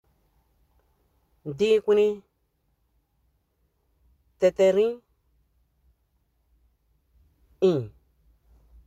Accueil > Prononciation > î > î